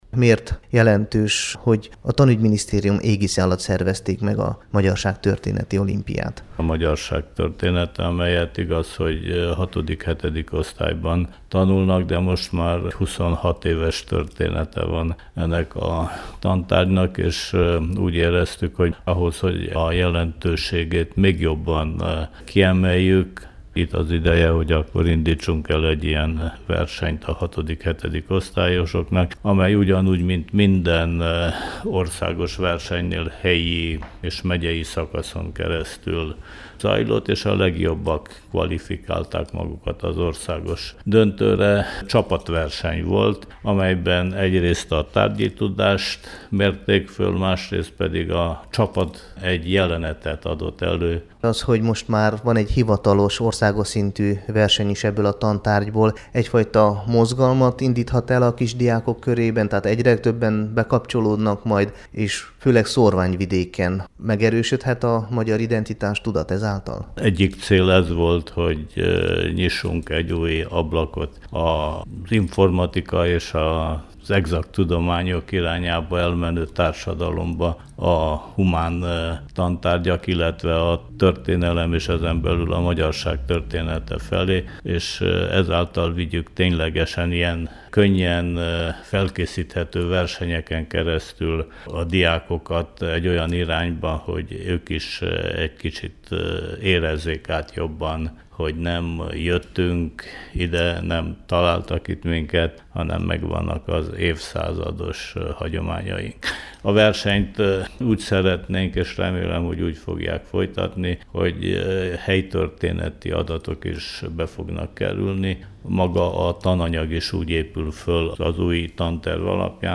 A hatodik-hetedik osztályos diákok számára szervezett vetélkedő fogadtatása pozitív volt, a csíkszeredai országos döntő után a kisebbségi oktatásért felelős bukaresti államtitkár, Király András elmondta, hogy biztosított a verseny a jövője, és a következőkben helytörténeti jellegű kérdésekkel is bővíteni szeretnék a feladványokat. Az oktatási szakember szerint nagyon fontos, hogy a történelemre legfogékonyabb korban találkozzon meg a magyar közösség új nemzedéke a hon- és nemzetismerettel, ezáltal erősítve az identitásukat.
a Kossuth Rádió Határok nélkül című nemzetpolitikai magazinja számára: